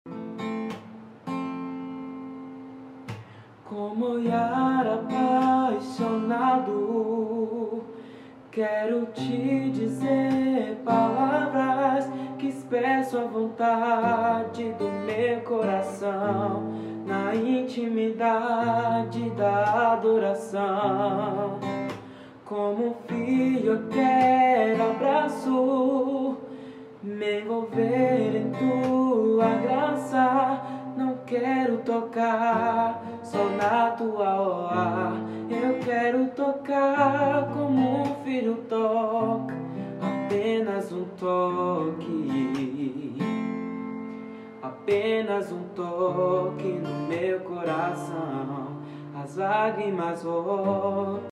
Apenas Um Toque❤‍🔥 Acústico Sound Effects Free Download